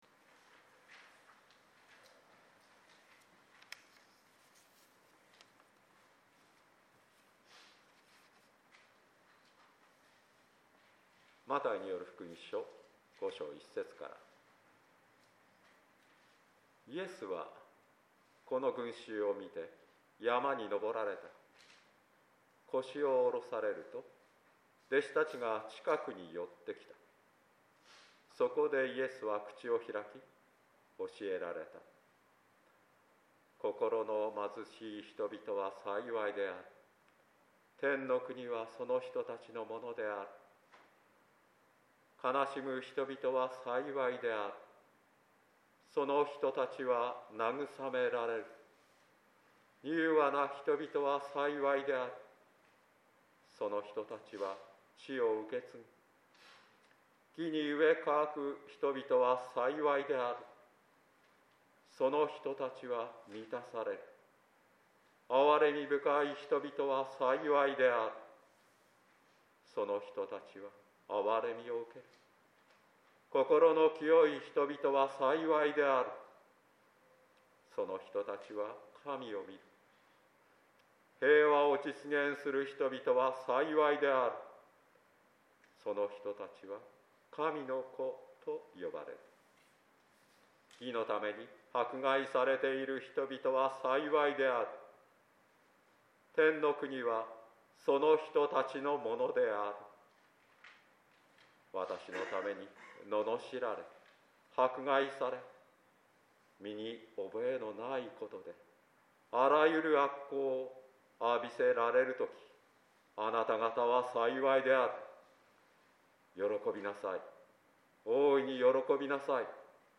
説教音声